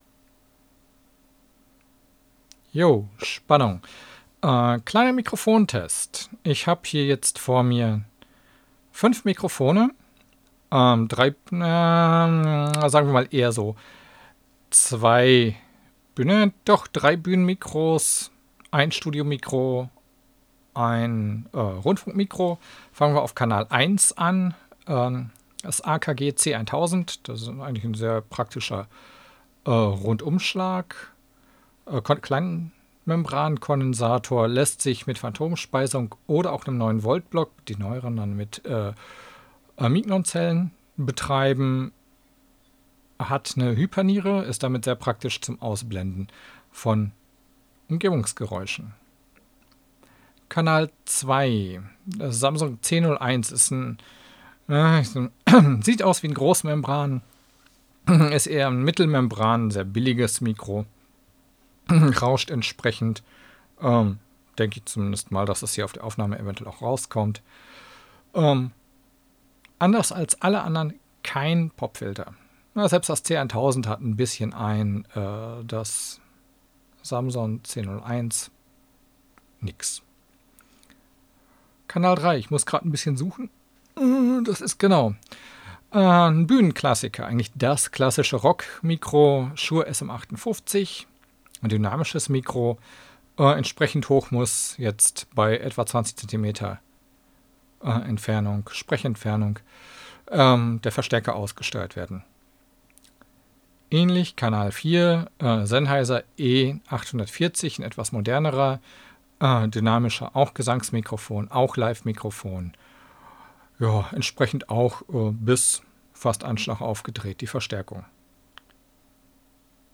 • Samson-C01 (billiges Studio Semi-Großmembran)
Alle wurden gleichzeitig an einem Zoom-H6 eingesprochenund mit ca. 25cm Abstand zwischen Mund und Mikro aufgenommen. Und ja. in Hintergrund rauscht ein PC.
Samson-C01.flac (5,7 MB)